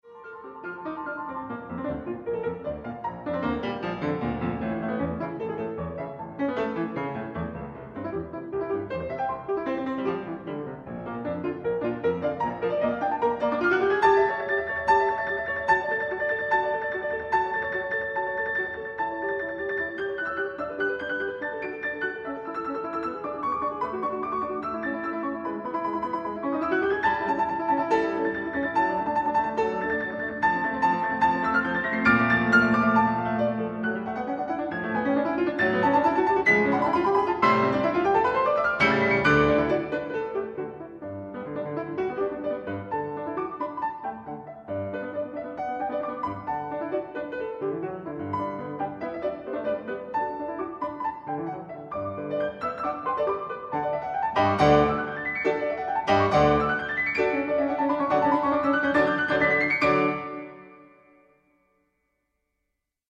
piano
Piano transcriptions